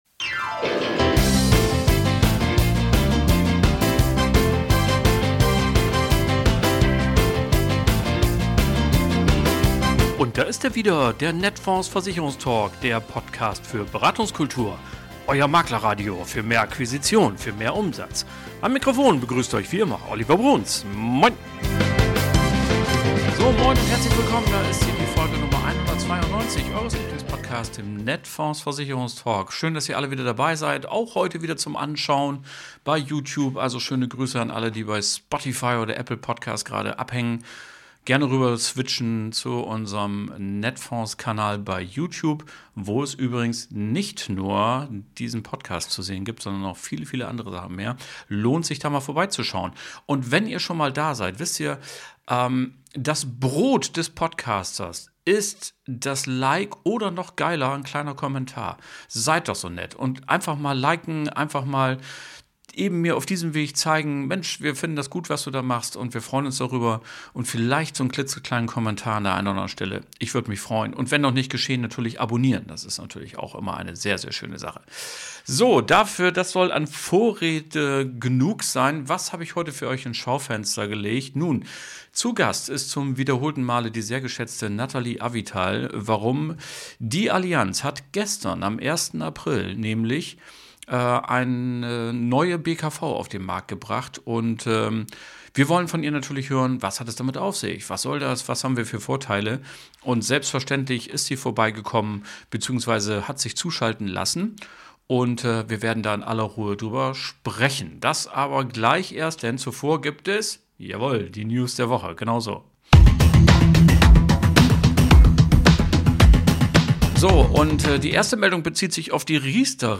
Hören Sie spannende Interviews und Reportagen mit praktischen Tipps oder vertiefenden Hintergrund-Informationen.